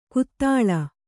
♪ kuttāḷa